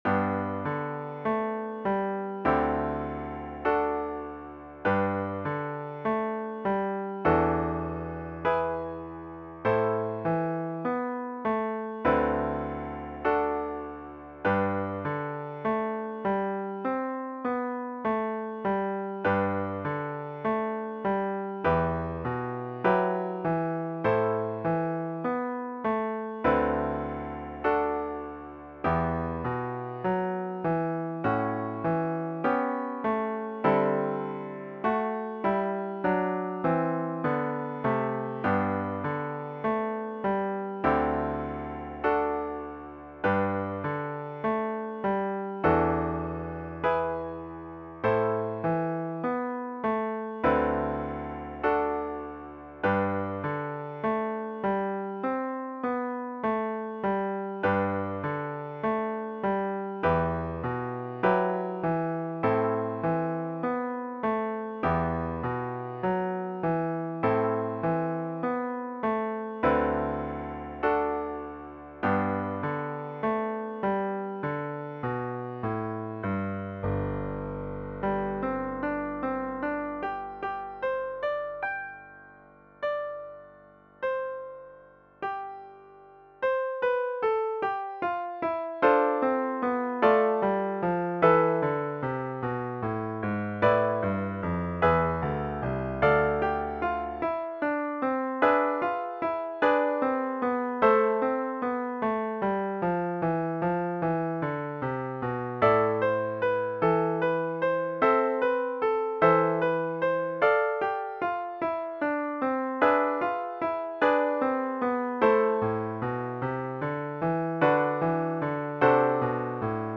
DIGITAL SHEET MUSIC - PIANO SOLO
Sacred Music, Piano Solo, Worship Services, Popular Hymn